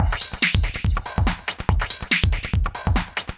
Index of /musicradar/uk-garage-samples/142bpm Lines n Loops/Beats
GA_BeatAFilter142-14.wav